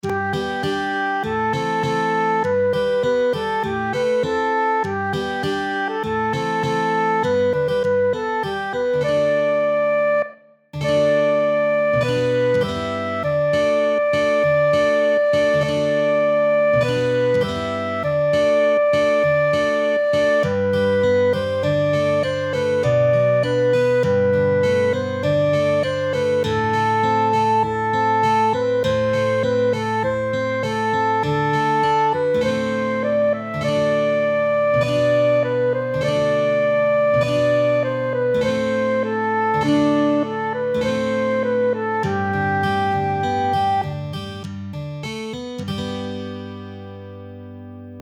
Livret de partition de « J’aime le pain », chanson de colonies de vacances traditionnelle avec paroles et musiques et les tablatures pour la guitare.